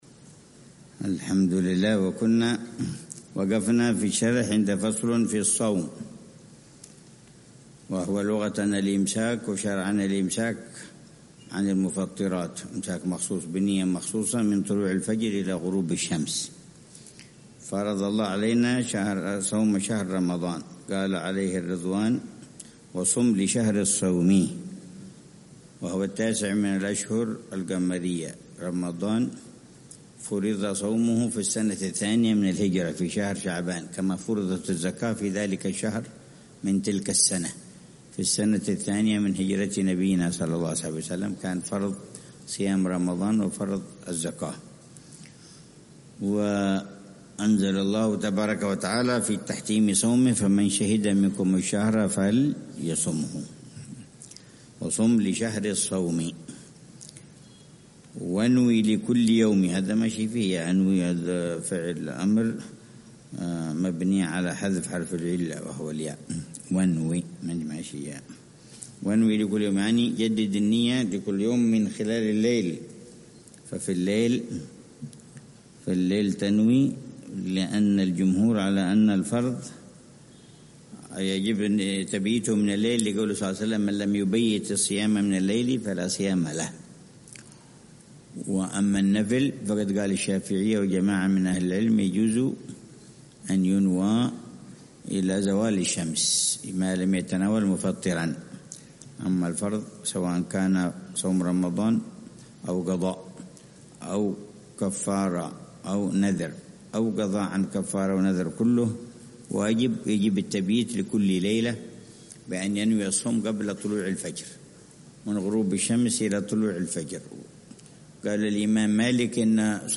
شرح الحبيب عمر بن حفيظ على منظومة «هدية الصديق للأخ والرفيق» للحبيب عبد الله بن حسين بن طاهر. الدرس الرابع والعشرون ( 14 صفر 1447هـ)